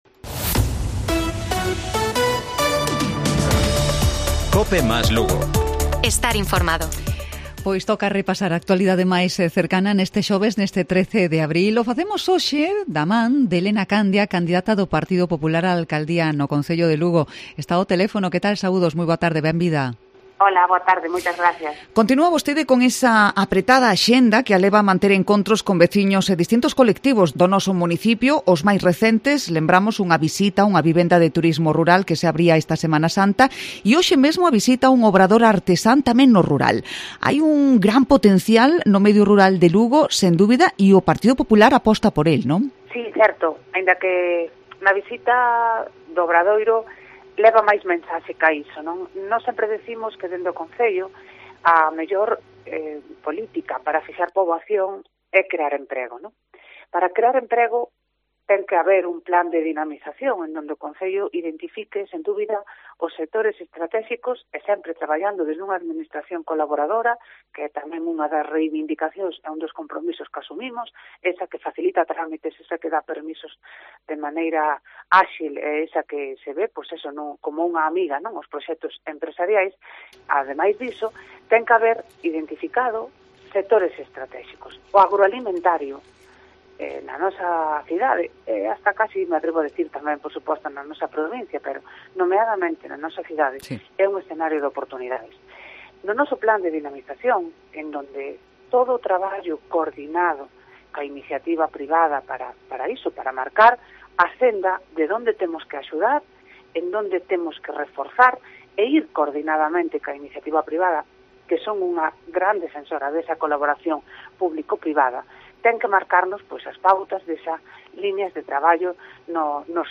Entrevista a Elena Candia en Cope Lugo